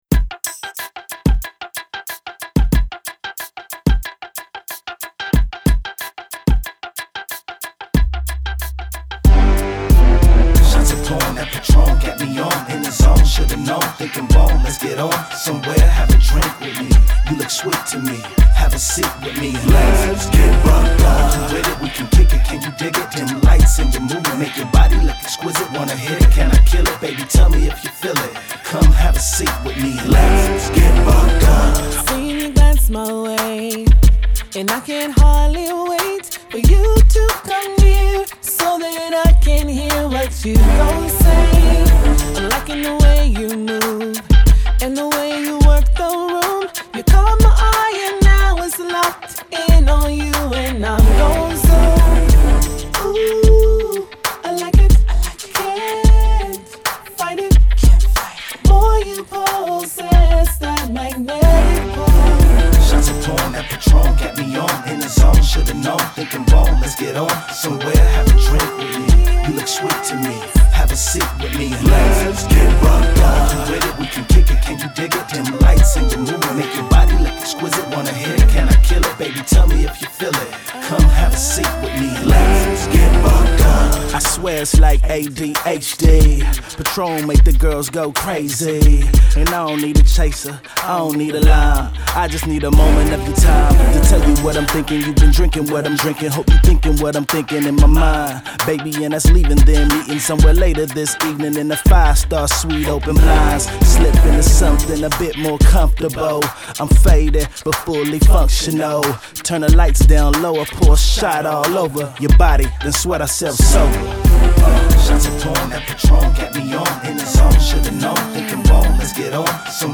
Hiphop
Description : A club banger..dance track